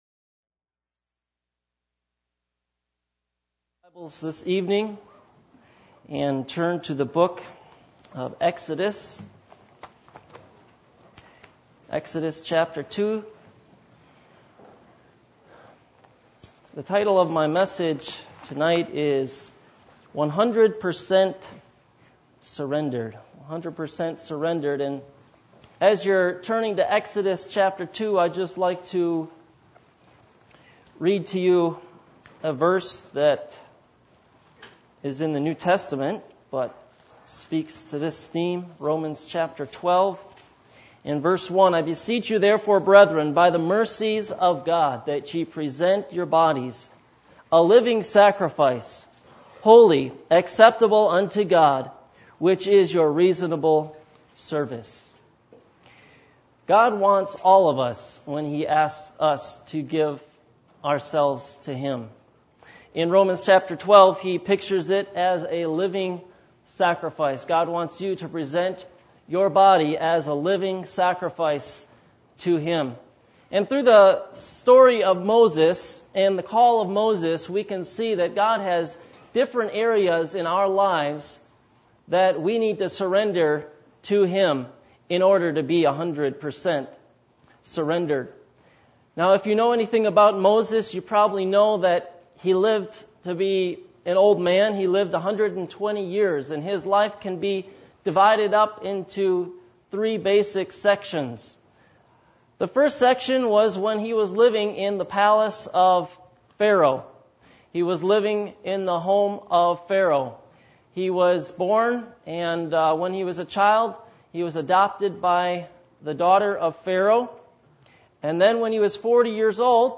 Passage: Hebrews 11:23-28 Service Type: Missions Conference